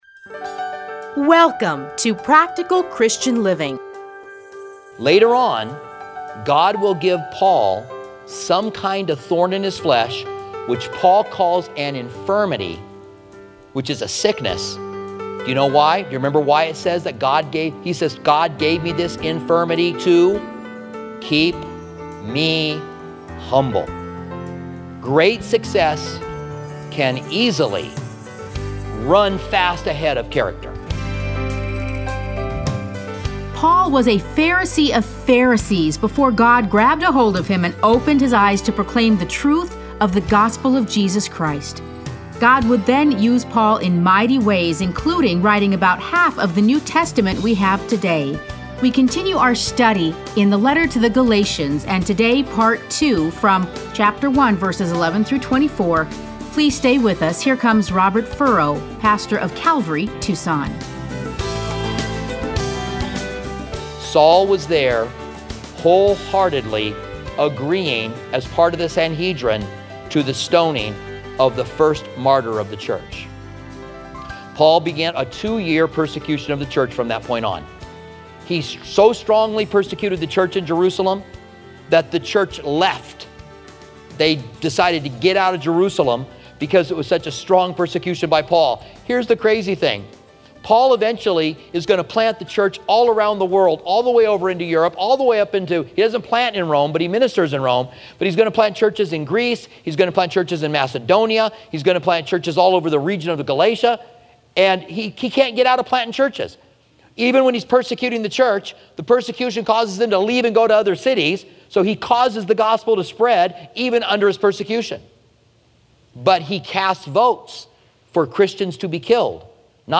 Listen to a teaching from Galatians 1:11-24.